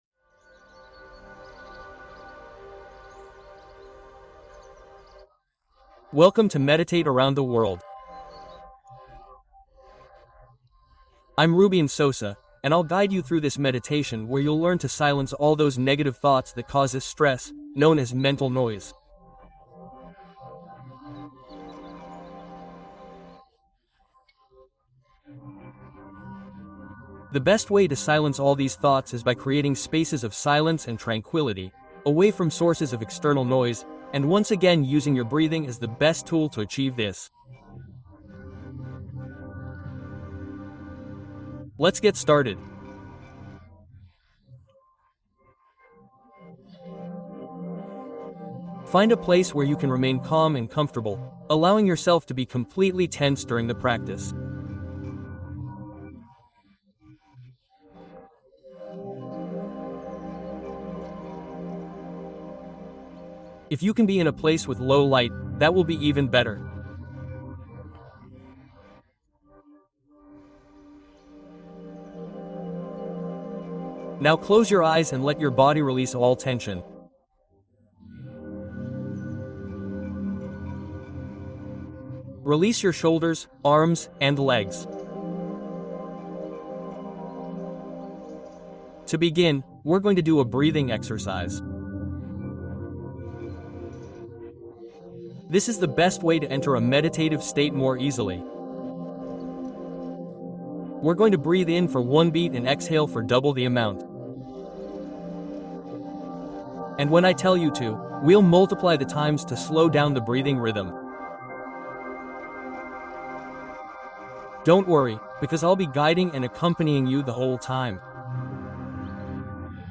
Silencia tu Mente: Meditación para Reducir Pensamientos y Crear Calma